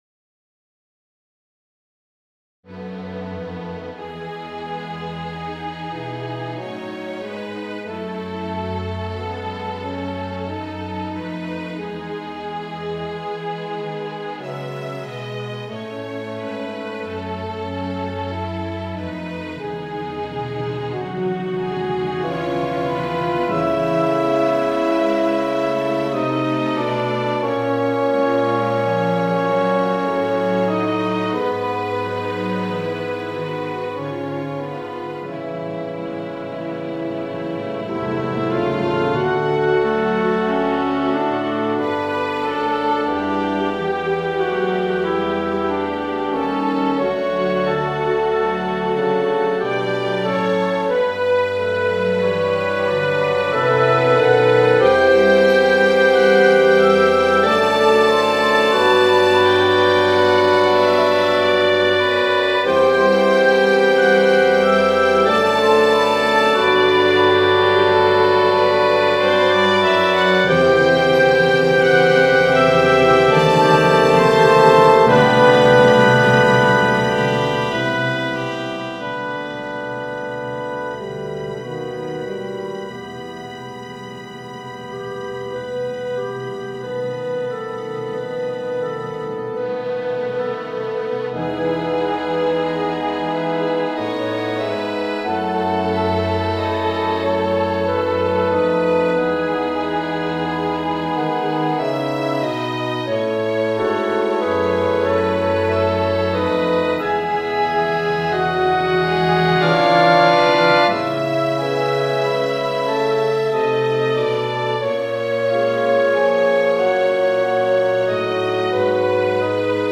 Da Camera
Momento musicale per Orchestra da Camera
2 Flauti
2 Oboi
2 Clarinetti in Sib
2 Fagotti
2 Corni in F
1 Tromba in Sib
Timpani
Violini I (8)
Violini II (6)
Viole (4)
Violoncelli (2)
Contrabbasso (1)